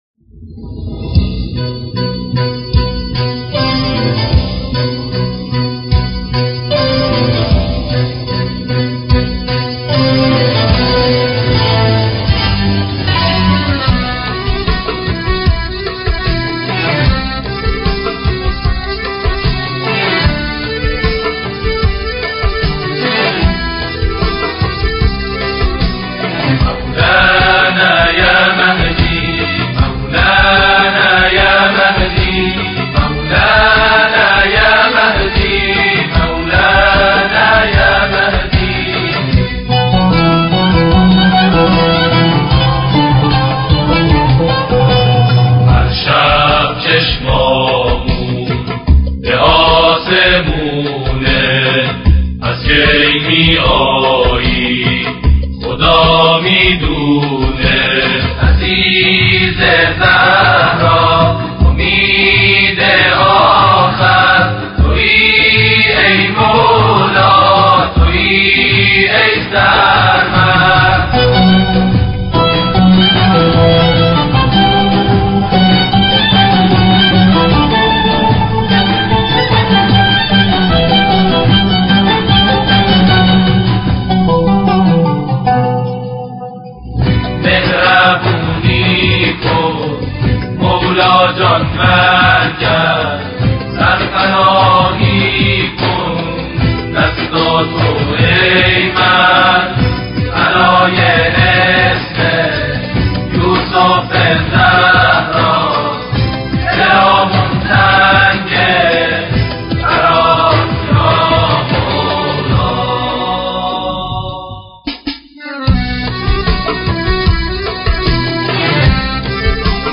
2.tavashih.mp3